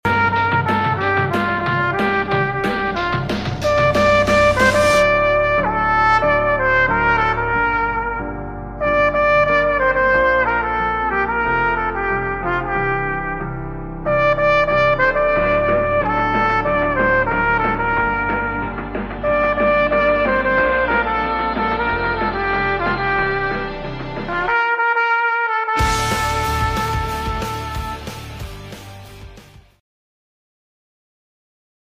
trompete